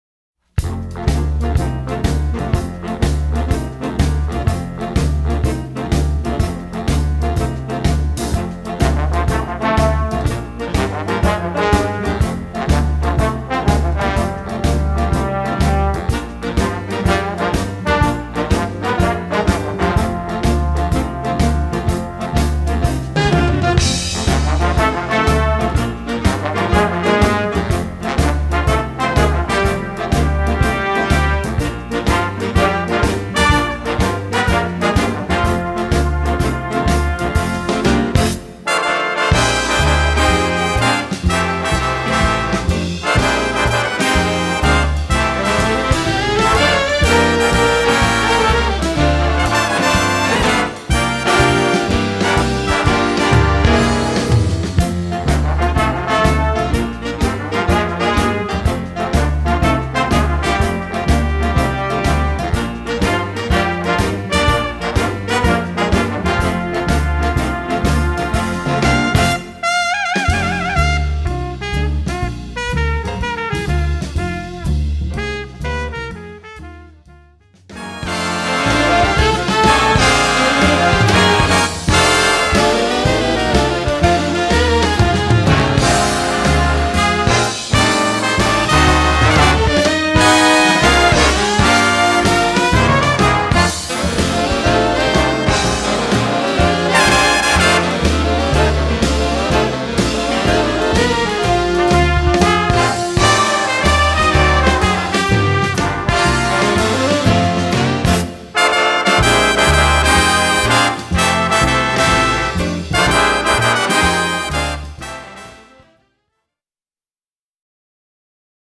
MUJ 輸入ジャズバンド（スコア＆パート）
Eb Alto Saxophone 1
Bb Tenor Saxophone 1
Eb Baritone Saxophone
Trumpet 1
Trombone 1
Guitar
Piano
Bass
Drums